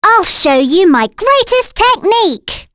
Reina-Vox_Skill5.wav